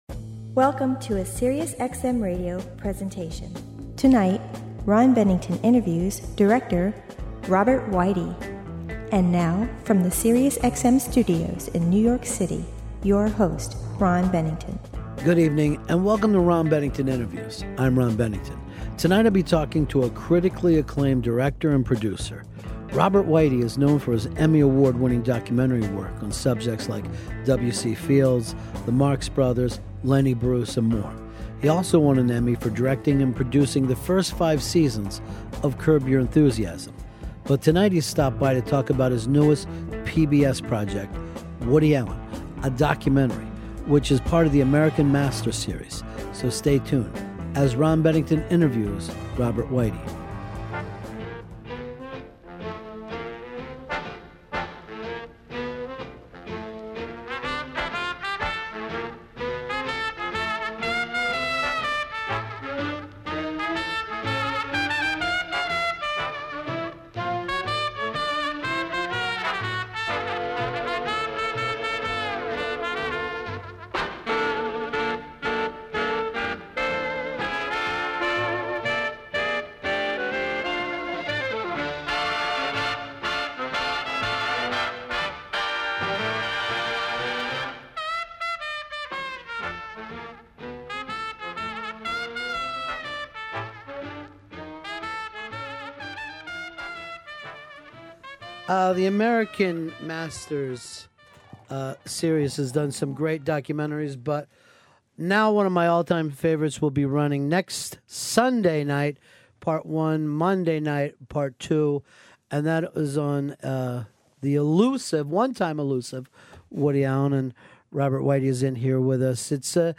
Ron Bennington interviews director Robert Weide.